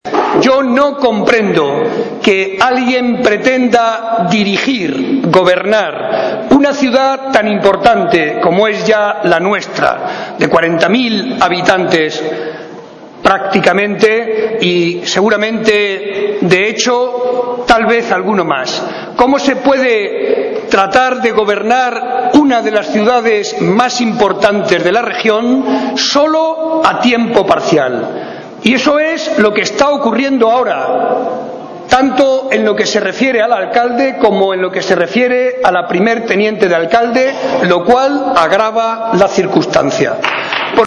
un acto en el que participaron cerca de 800 personas